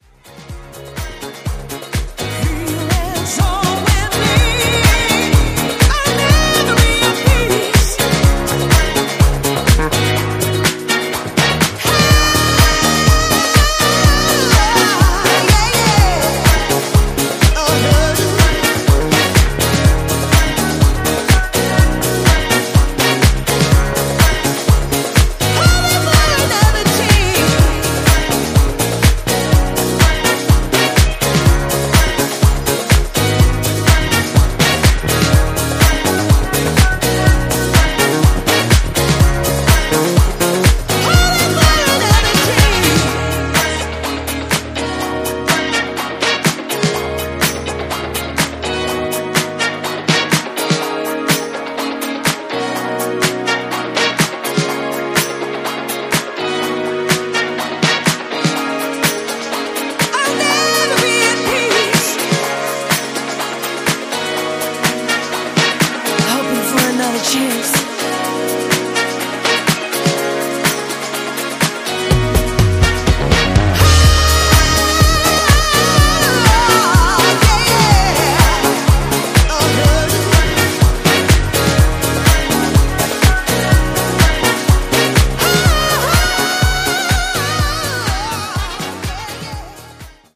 French House
disco beauty